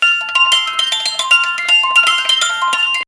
Play, download and share music boxxxxx original sound button!!!!
music-boxxxxx.mp3